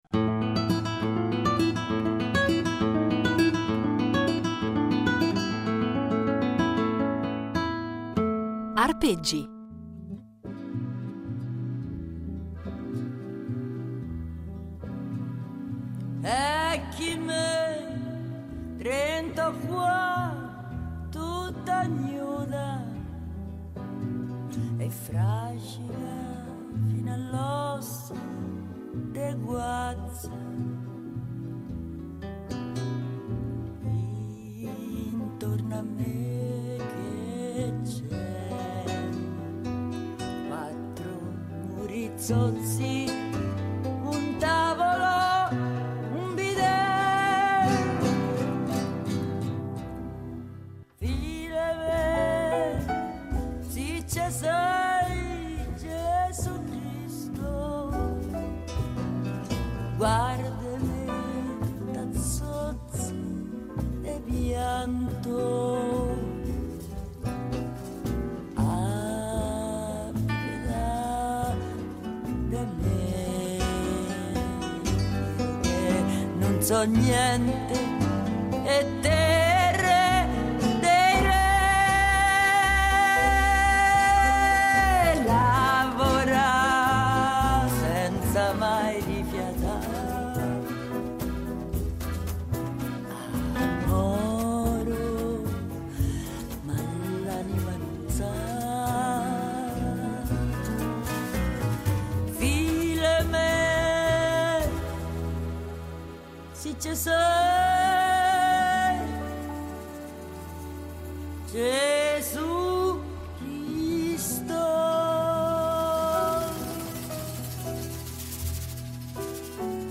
chitarra
cantante